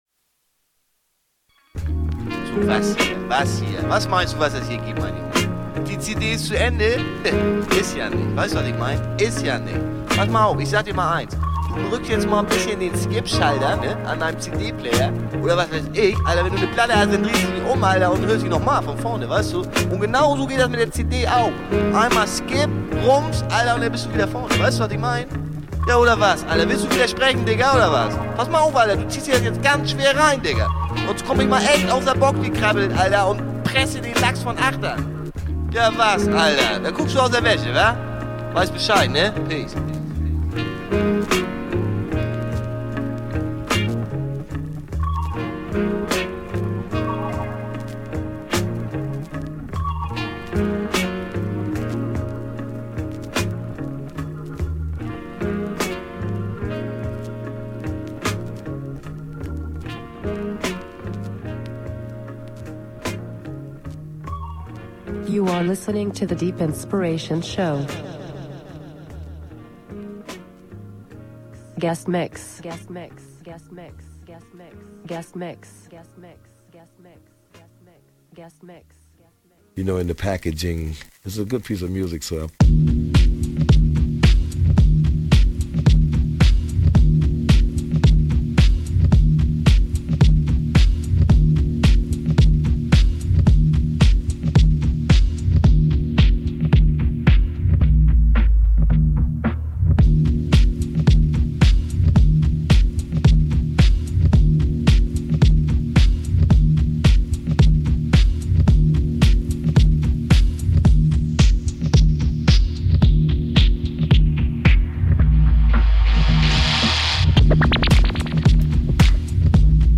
very deep, oldschool, raw and soulful mix
killer mixtape